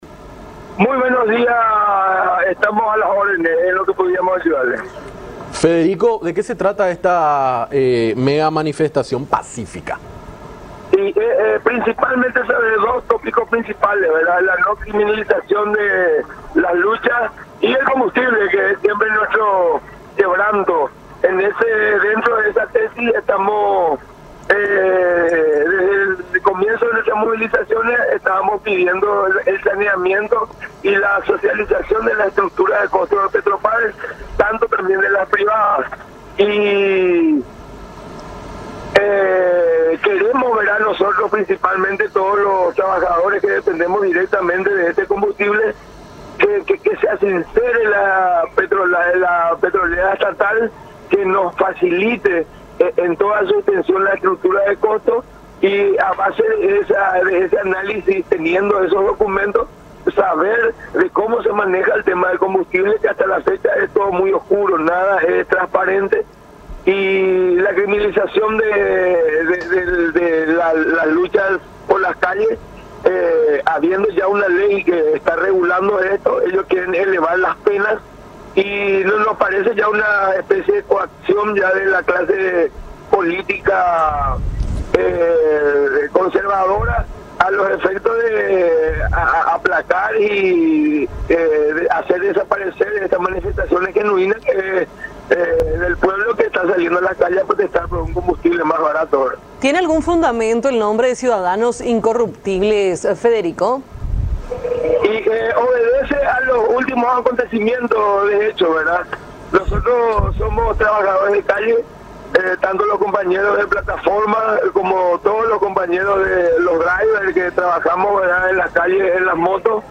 en contacto con Nuestra Mañana por Unión TV